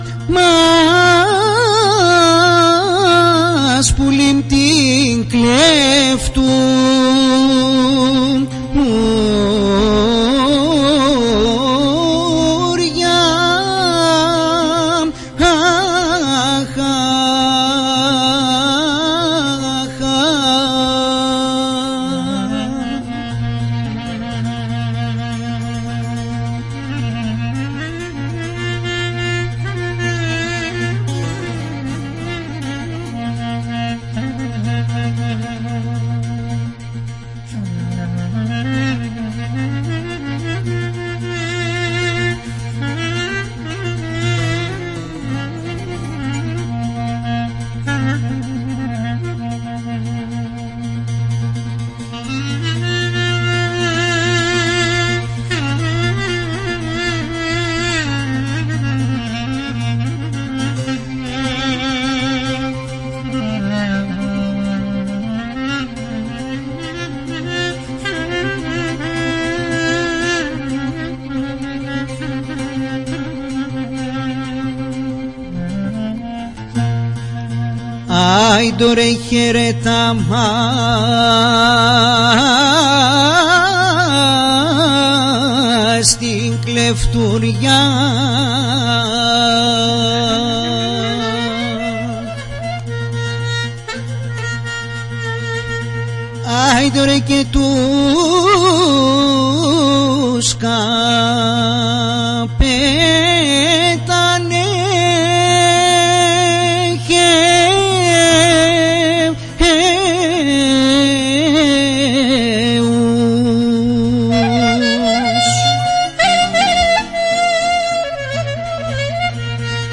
Τα τραγούδια παρουσίασε ζωντανά η «Ορχήστρα των Τρικάλων» με τραγουδιστή
κλαρίνο
βιολί
λαούτο